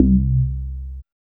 3008R BASS.wav